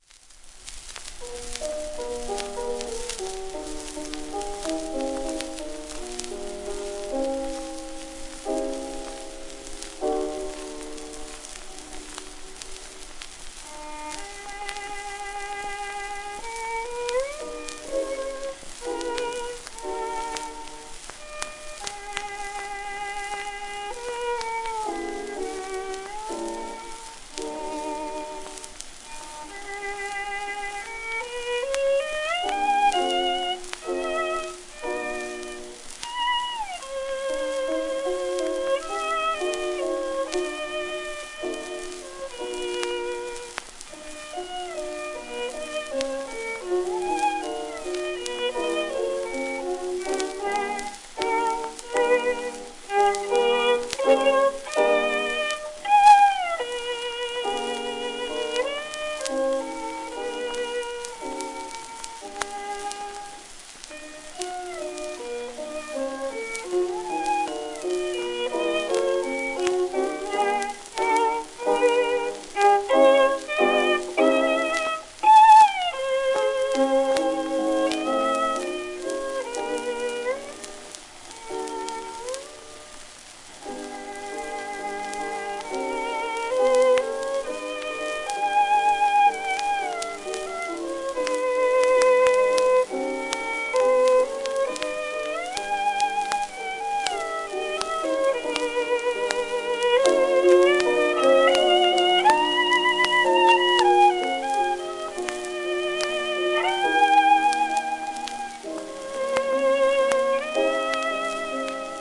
1922年頃録音、80rpm
旧 旧吹込みの略、電気録音以前の機械式録音盤（ラッパ吹込み）